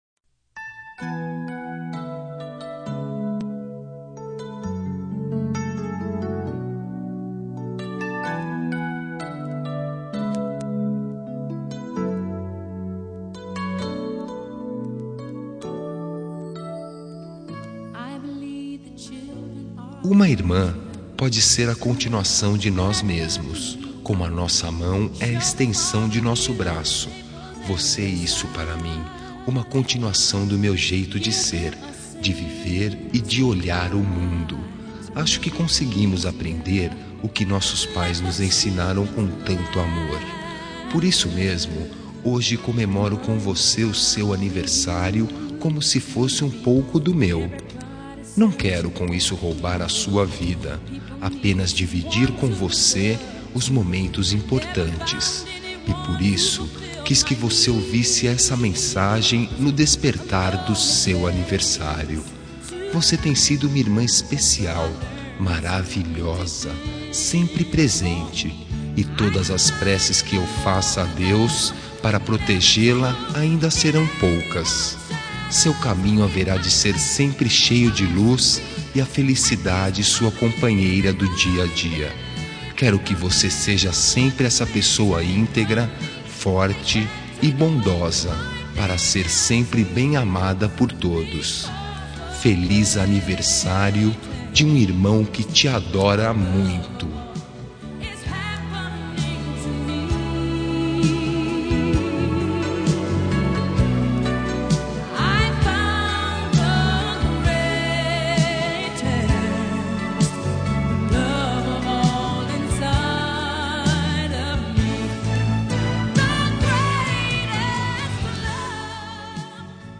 Telemensagem de Aniversário de Irmã – Voz Masculina – Cód: 1668